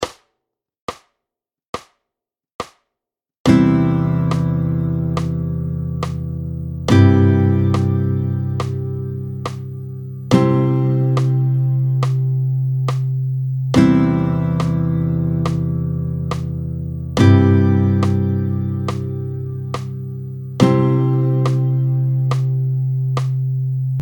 11-04 Do avec différentes basse : la basse mi, la sol et la do, tempo 70